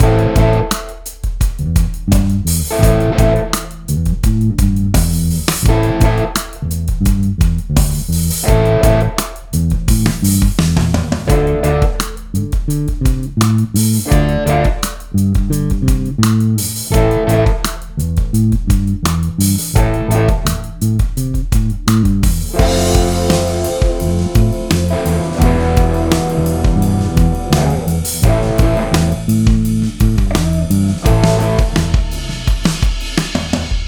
Loops WAVs
ThrillLoop.wav